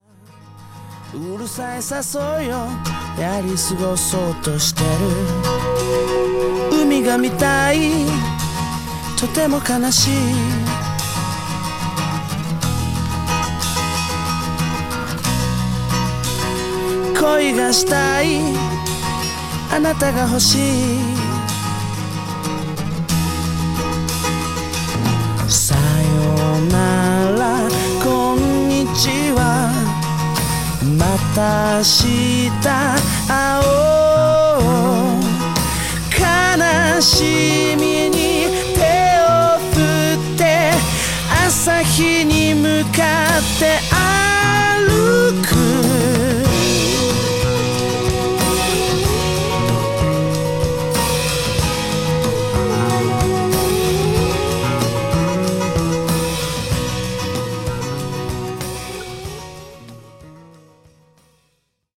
清涼感あふれるポップスナンバー10曲を収録した2007年作。